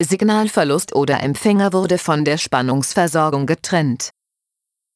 Ich habe sie hier nochmal eingestellt ohne die Pause falls jemand die alternative Ansage für die fehlende Spannungsversorgung am Empfänger mag.
Signalverlusst_.wav